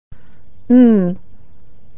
Consonant Sound /n/ - Practice - Compare - Authentic American Pronunciation